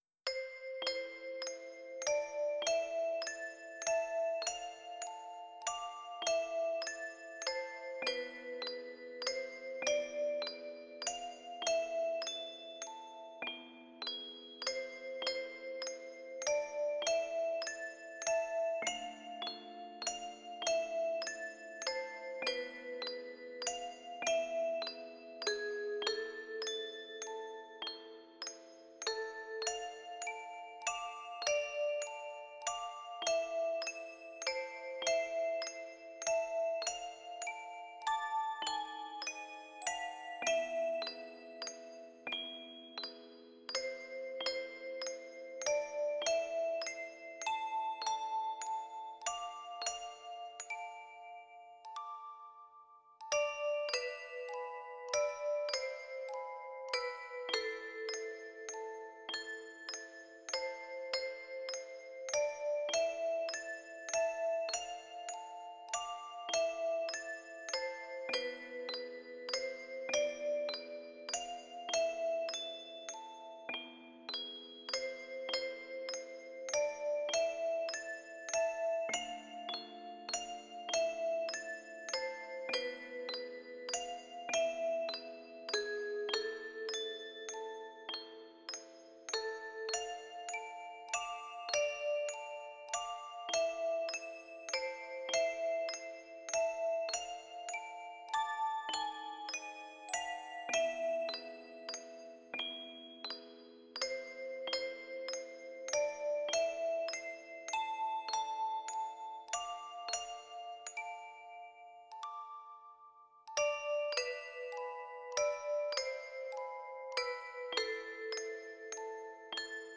Dark arrange version of famous works